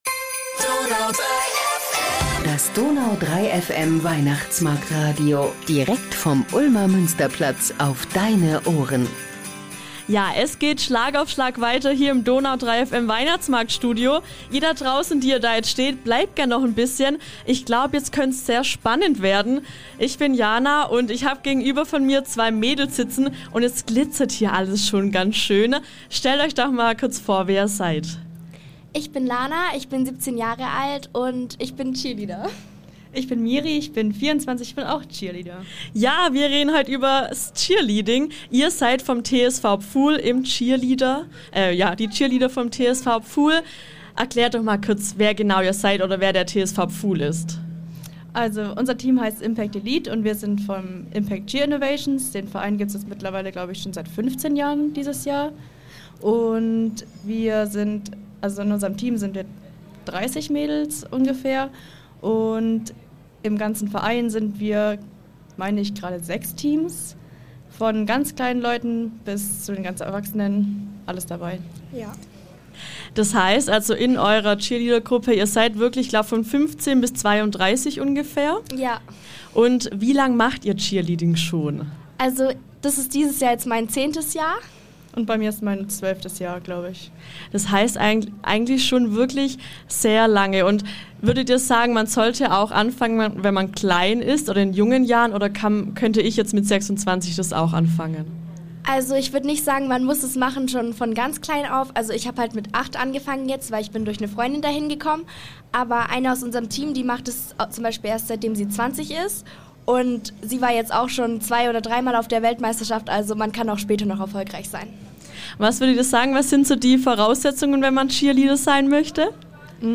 Die Cheerleader "Impact Elite" des TSV Pfuhl 1894 e.V. im Weihnachtsmarktstudio ~ Ulmer Weihnachtsmarkt-Podcast Podcast